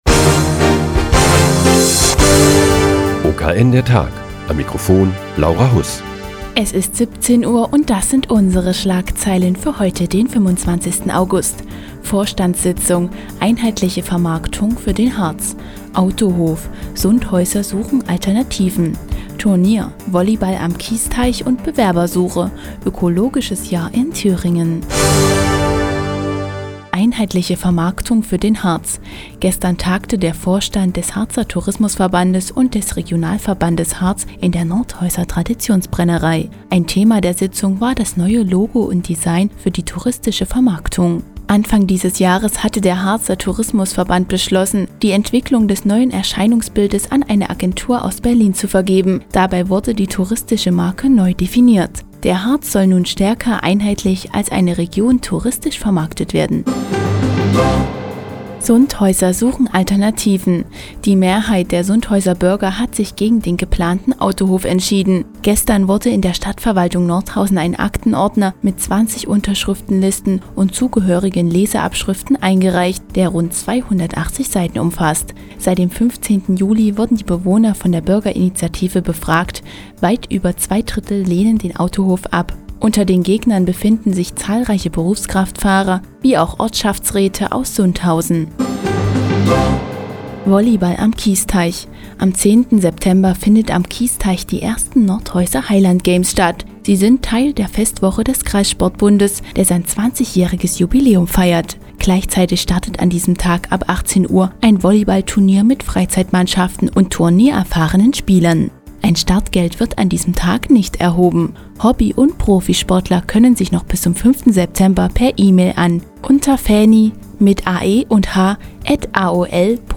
Die tägliche Nachrichtensendung des OKN ist nun auch in der nnz zu hören. Heute geht es um die Vorstandssitzung des Harzer Tourismusverbandes und die ersten Nordhäuser- Highland- Games am Kiesteich